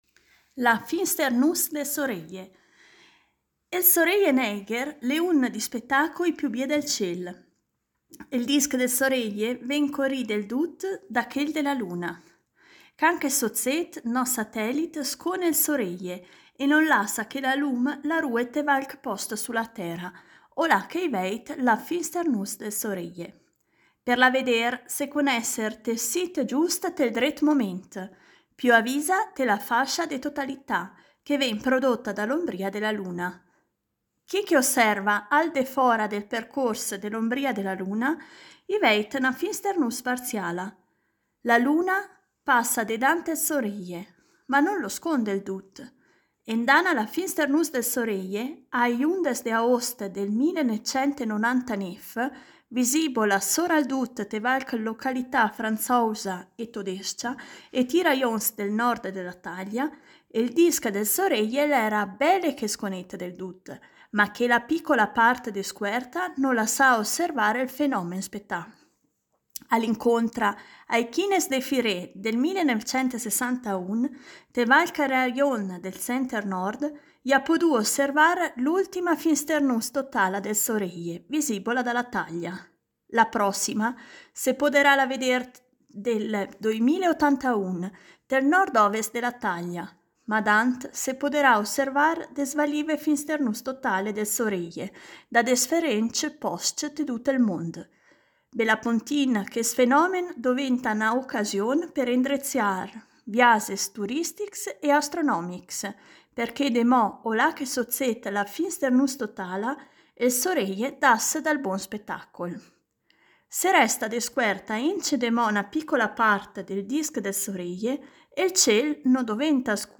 Il progetto “Idiomi celesti” promuove l’osservazione ad occhio nudo del cielo stellato con testi scritti e letti ad alta voce in lingua ladina e in dialetto lumezzanese.